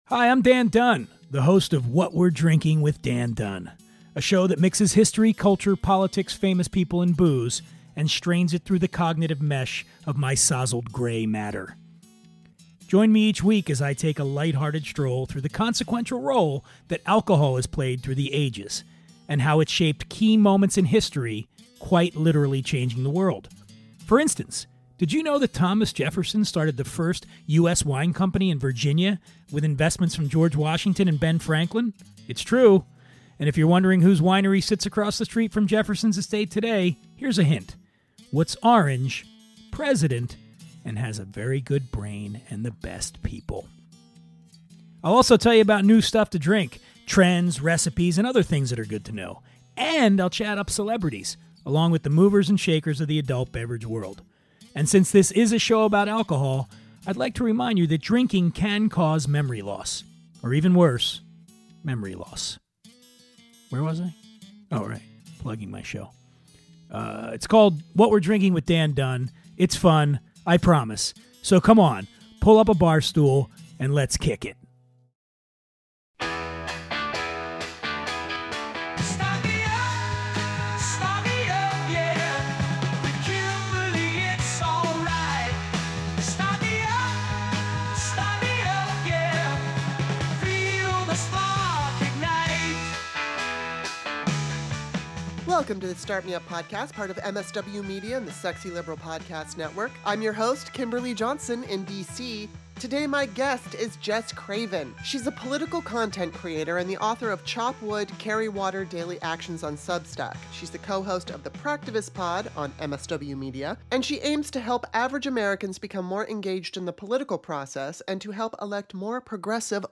I'm not always politically correct and I'm a huge fan of loose talk and salty language. Sarcasm is guaranteed and political correctness will be at a minimum. This podcast is for anyone who wants to listen to compelling conversations with a variety of guests about current events and controversial topics.